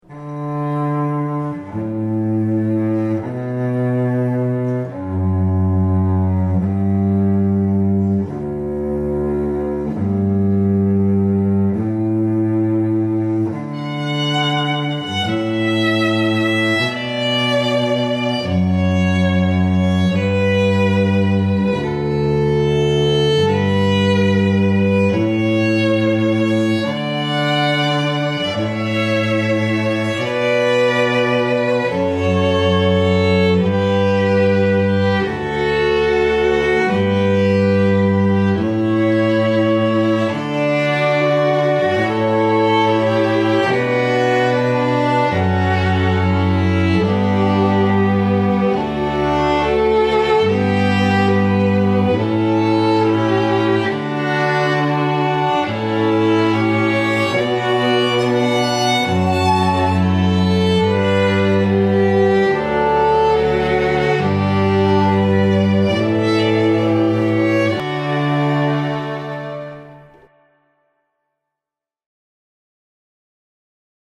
North East String Quartet for Hire
The quartet are also available as a string trio (violin, viola, cello) in order to cater for your venue space and budgetary needs.